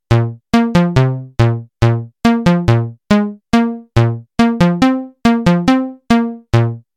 The program I implemented essentially “evolves” a simple musical grammar based on the user’s tastes and renders the sounds using a synthesized bass patch.